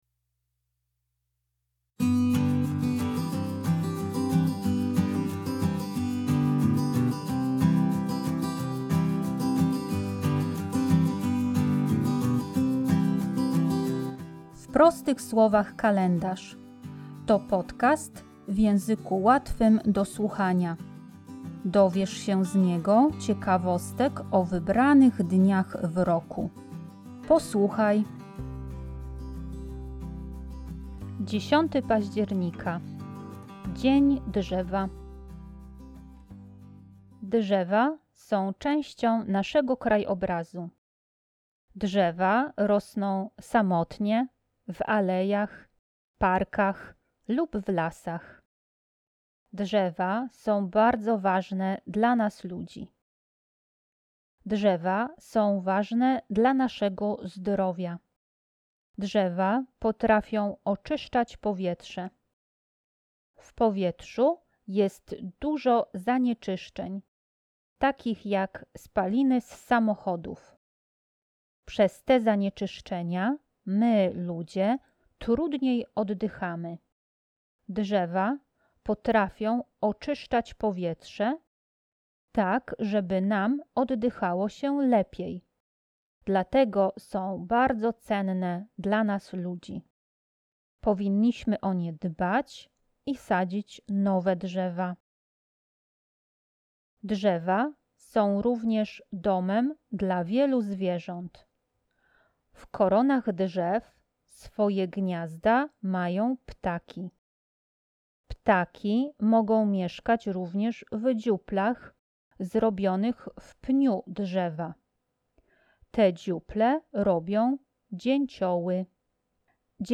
W podcaście słychać dźwięki stukania dzięcioła w drzewo